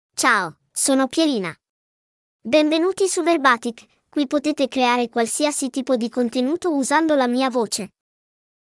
PierinaFemale Italian AI voice
Pierina is a female AI voice for Italian (Italy).
Voice sample
Female
Pierina delivers clear pronunciation with authentic Italy Italian intonation, making your content sound professionally produced.